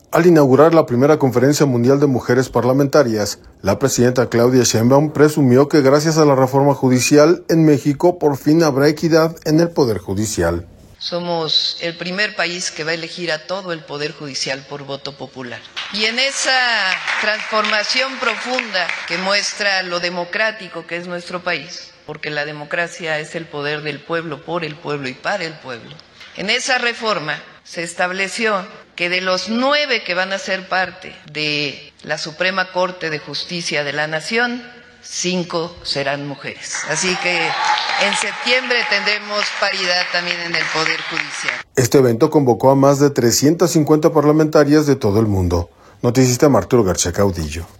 Al inaugurar la primera Conferencia Mundial de Mujeres Parlamentarias, la presidenta Claudia Sheinbaum presumió que gracias a la reforma judicial en México por fin habrá equidad en el Poder Judicial.